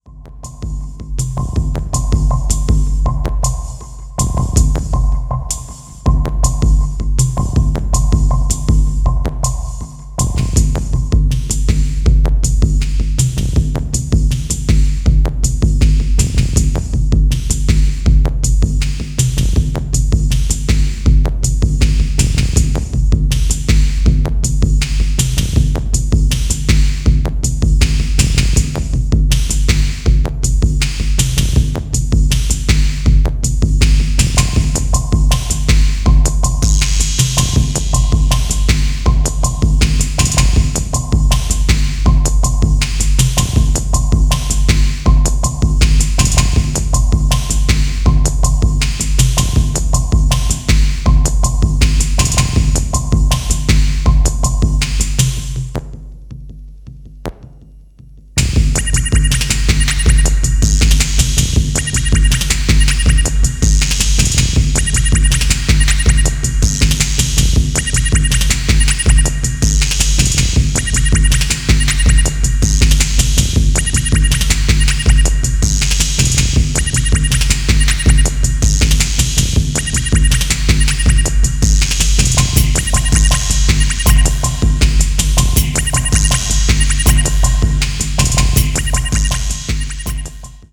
今度はディープ・テクノからのポストDNB模範解答を提出